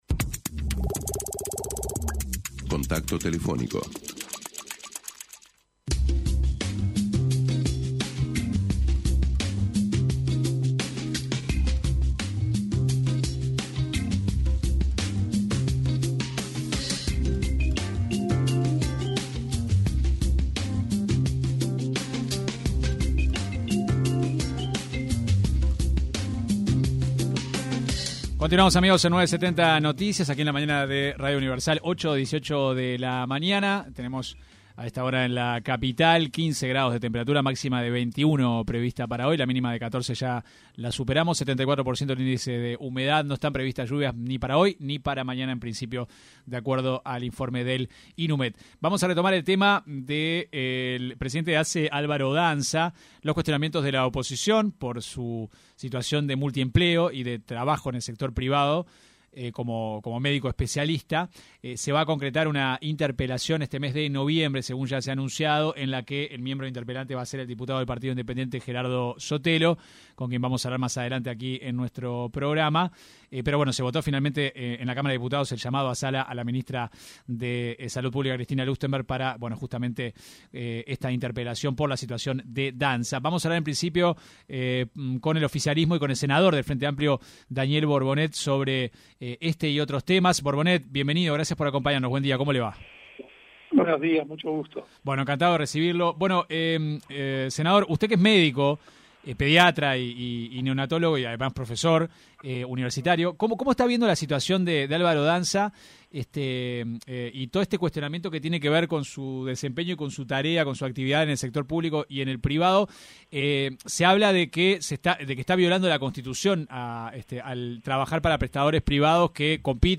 El senador del Frente Amplio, Daniel Borbonet, se refirió en una entrevista con 970 Noticias, a los dichos de su correligionario y presidente de la Cámara de Diputados, Sebastián Valdomir, quien señaló que el presidente de Asse, Álvaro Danza recibe un sueldo exiguo para el cargo que ocupa.